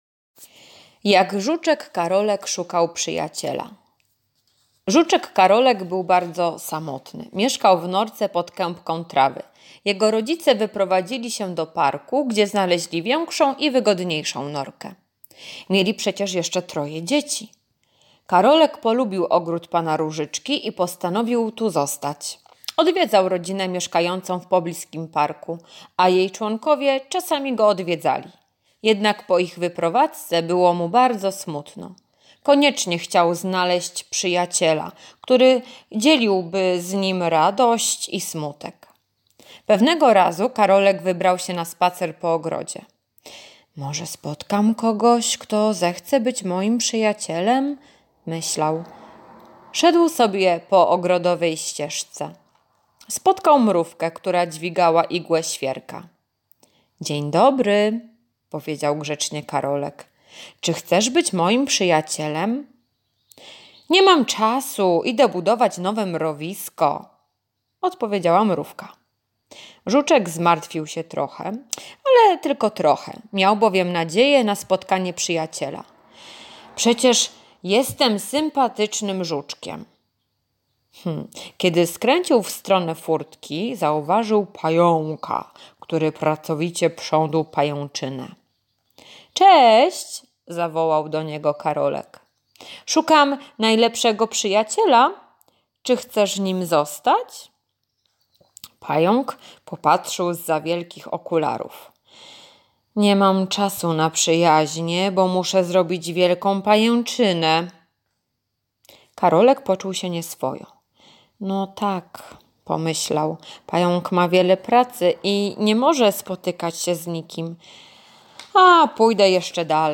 piątek- słuchowisko "Żuczek Karolek" [7.17 MB] RELIGIA [171.40 kB]